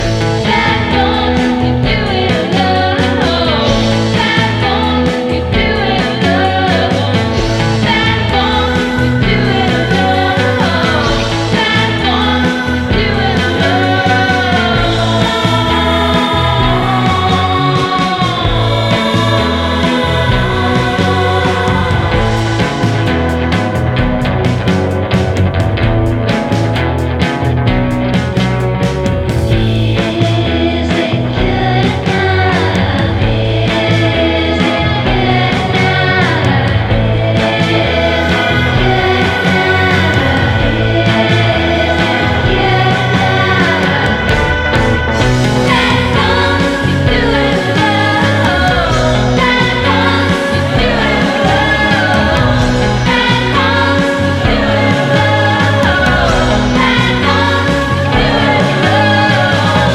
INDIE POP / SYNTH WAVE
ドリーミーでサイケデリックな2021年ガール・シンセ・インディー・パンク！
全編にわたって幻覚的な音響とナイーヴなメロディーが交錯。